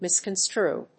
音節mis･con･strue発音記号・読み方mɪ̀skənstrúː
• / mɪskənˈstruː(米国英語)